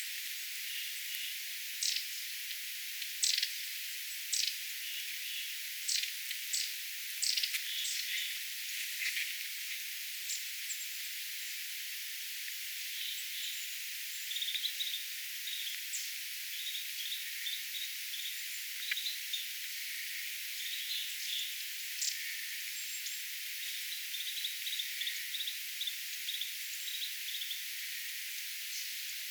erikoista karheampaa
hömötiaisen huomioääntä
erikoista_karheampaa_homotiaislinnun_huomioaanta.mp3